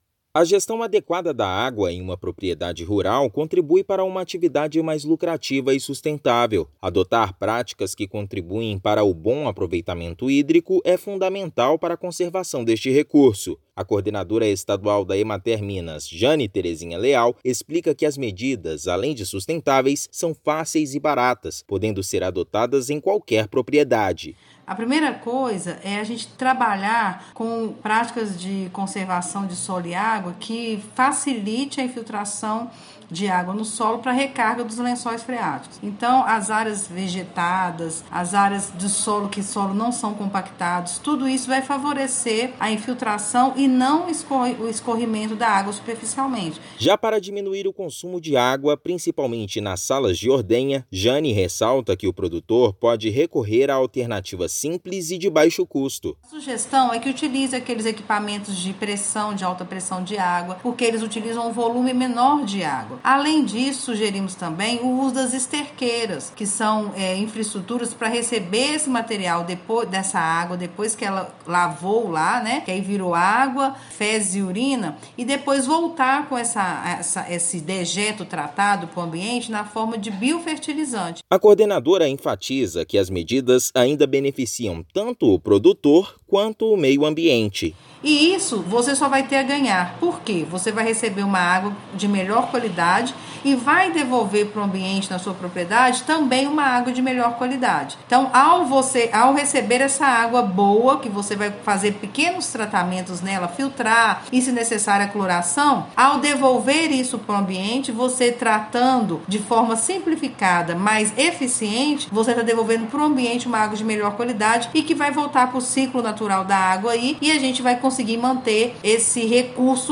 [RÁDIO] Emater-MG orienta produtores para uso eficiente e sustentável da água no campo
A gestão adequada dos recursos hídricos nas propriedades rurais contribui para que a atividade seja ambientalmente saudável e mais lucrativa. Ouça matéria de rádio.